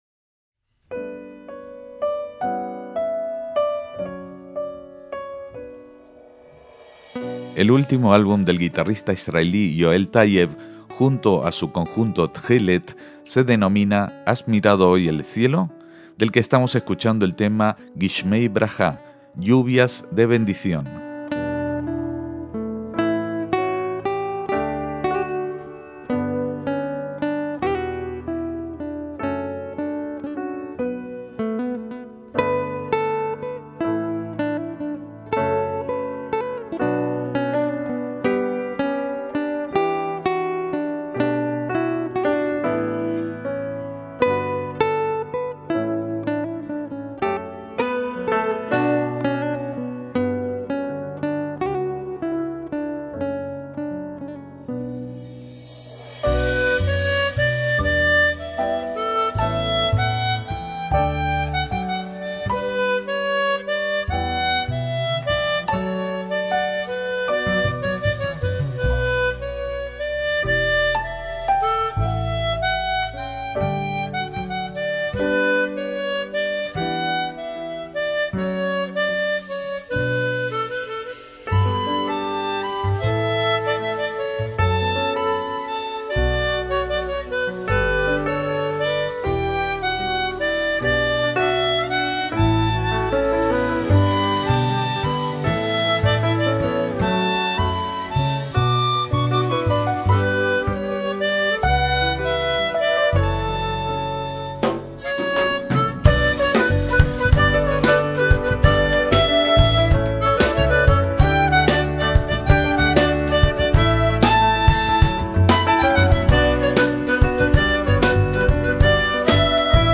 un estilo de música actual mezclando melodías originales inaspiradas en la tierra de Israel y el arte de la improvisación